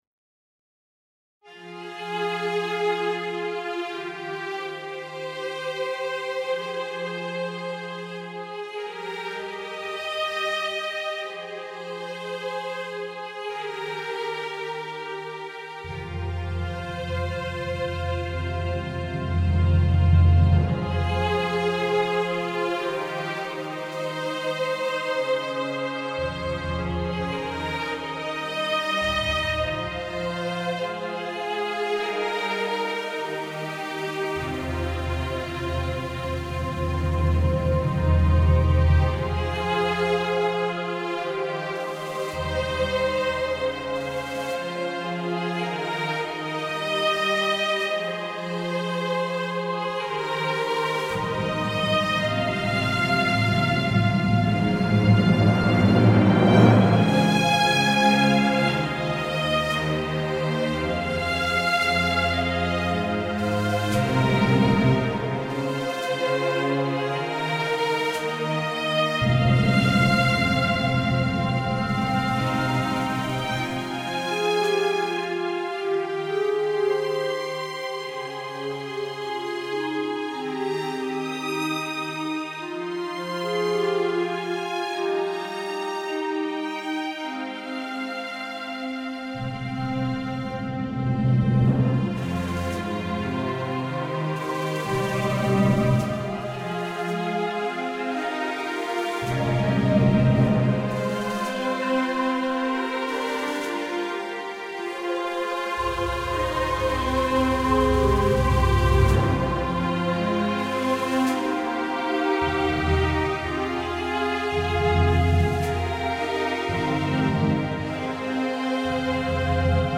soundtrack/game music